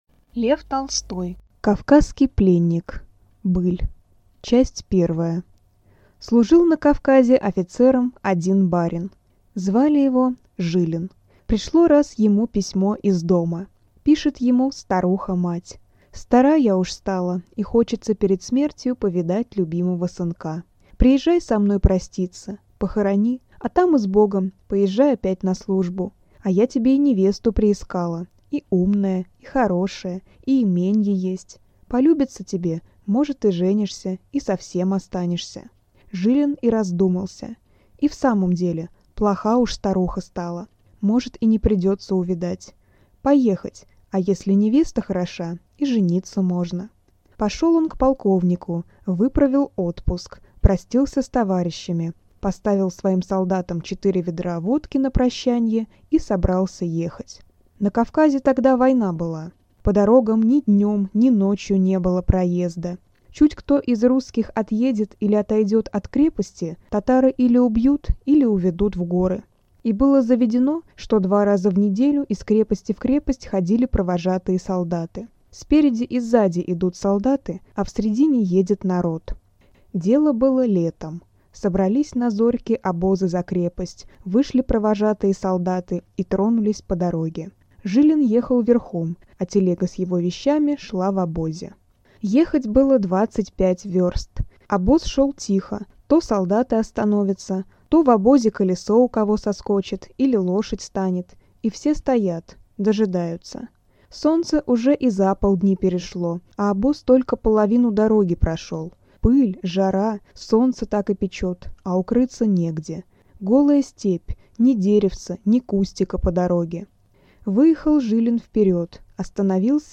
Аудиокнига Кавказский пленник | Библиотека аудиокниг